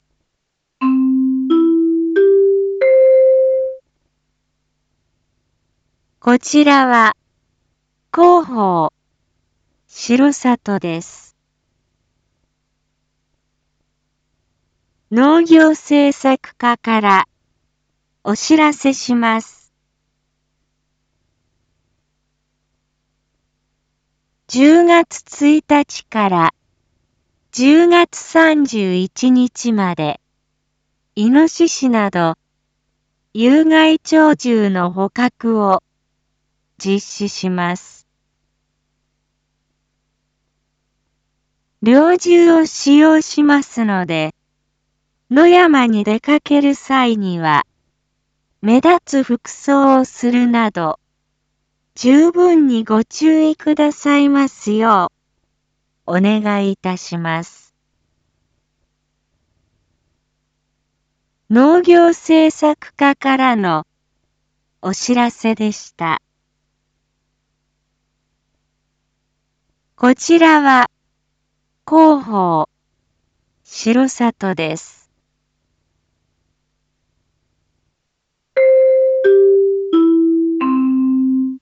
一般放送情報
Back Home 一般放送情報 音声放送 再生 一般放送情報 登録日時：2024-10-14 07:01:23 タイトル：⑩有害鳥獣捕獲について インフォメーション：こちらは、広報しろさとです。